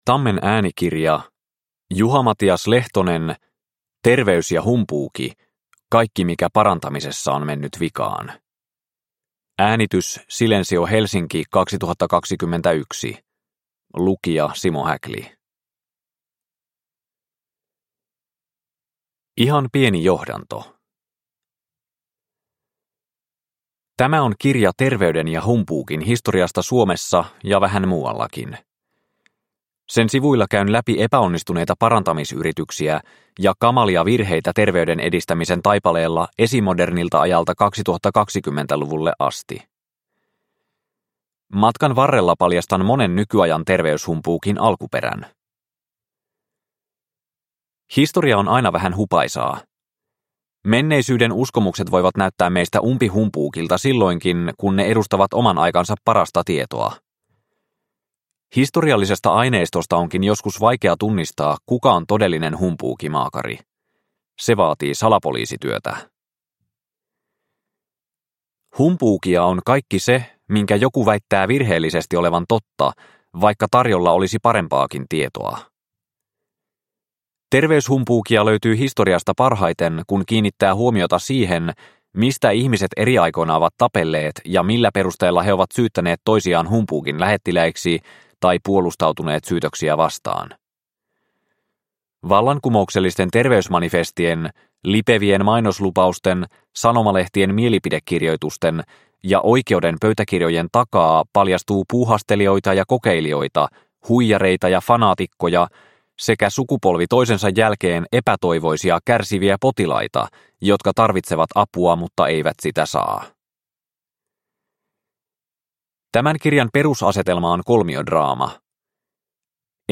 Terveys ja humpuuki – Ljudbok – Laddas ner